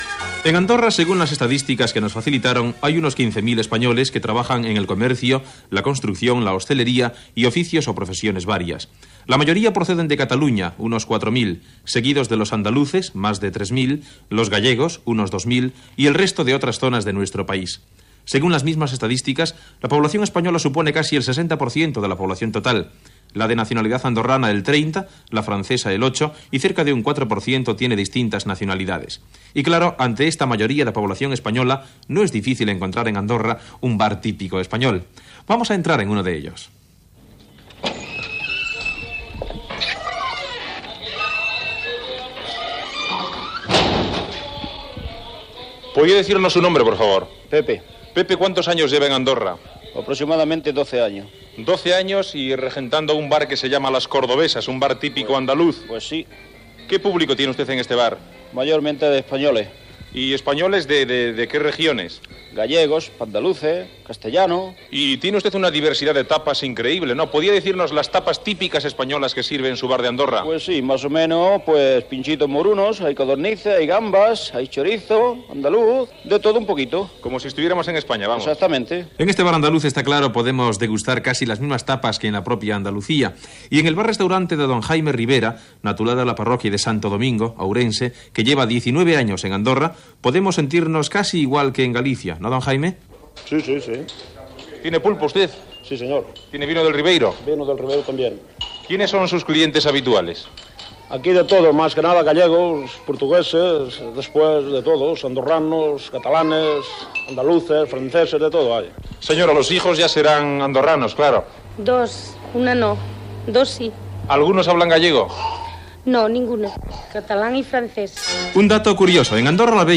Reportatge sobre els espanyols que treballen al Principat d'Andorra
Informatiu